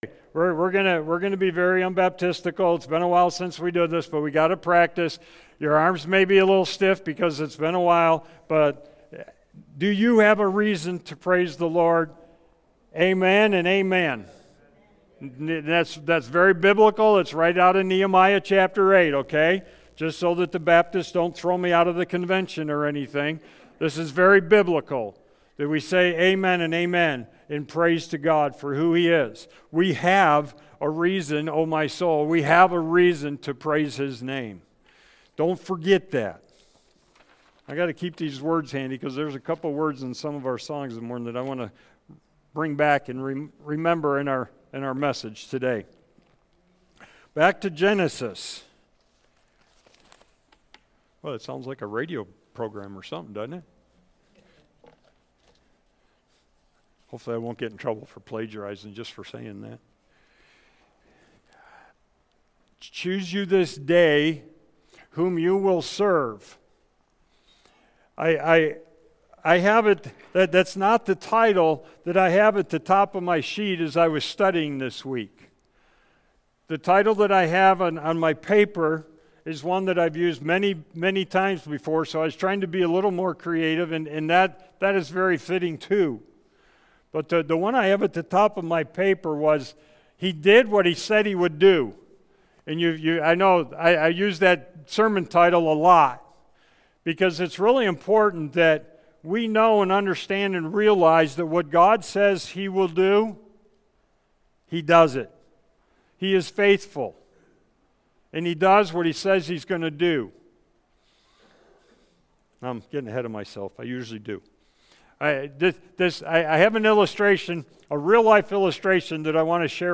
Sermons by First Baptist Church of Newberry Michigan